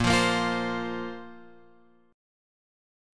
A#sus2 chord {x 1 3 3 1 1} chord